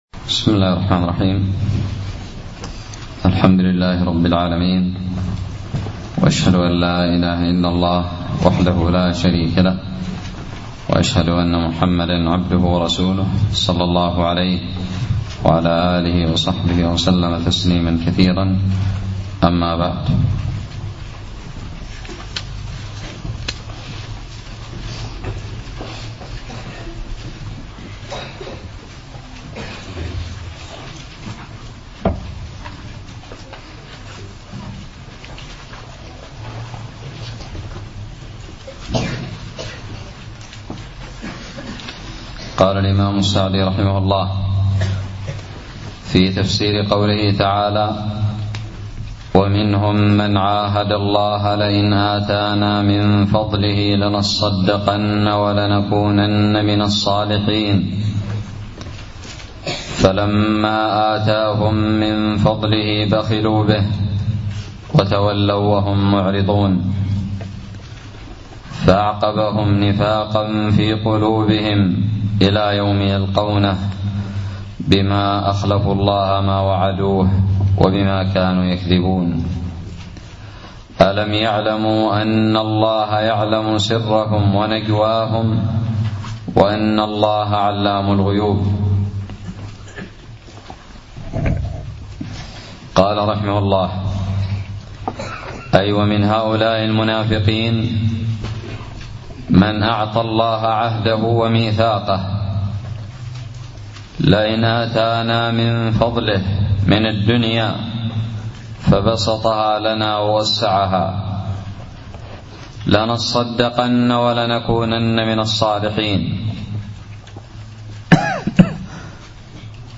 الدرس الرابع والثلاثون من تفسير سورة التوبة
ألقيت بدار الحديث السلفية للعلوم الشرعية بالضالع